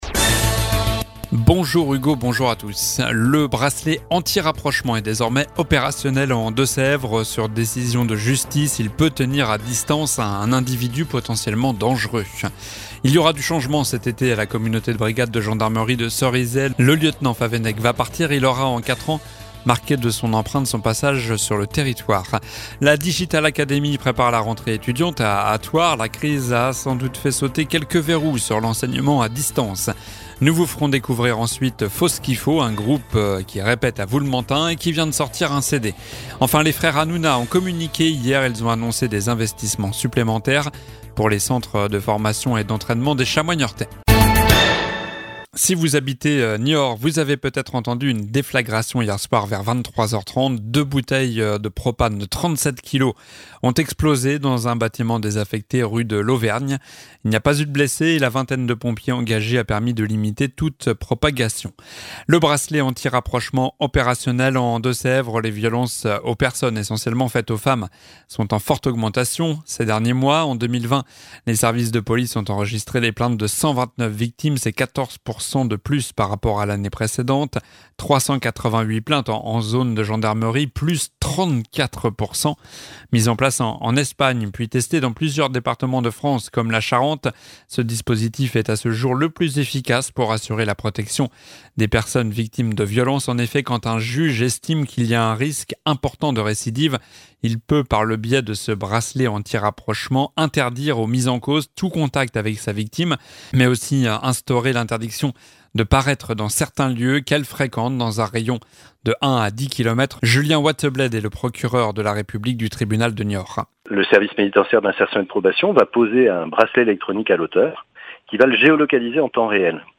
Journal du vendredi 28 mai (midi)